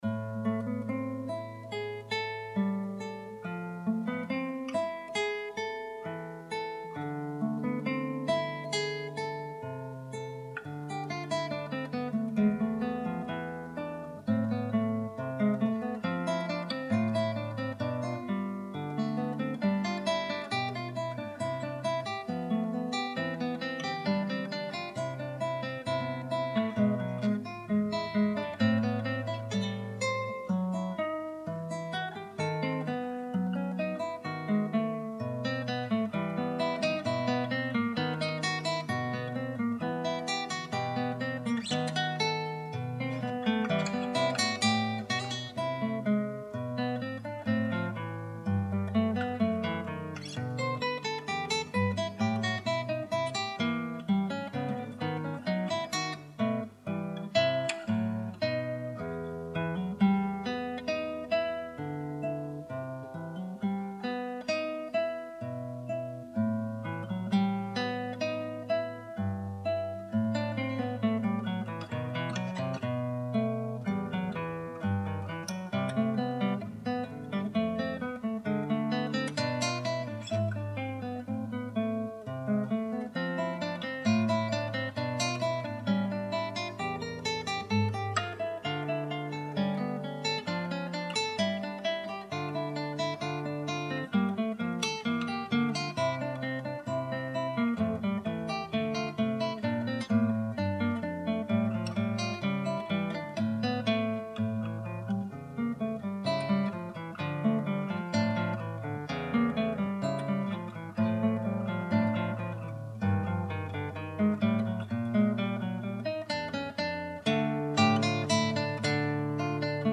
Using an SM57 and an 58 he is not sure is genuine! Soundcraft 8fx USB mixer.
Attachments classical gtr baleqd01.mp3 classical gtr baleqd01.mp3 5.2 MB · Views: 313